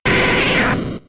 Cri de Carabaffe K.O. dans Pokémon X et Y.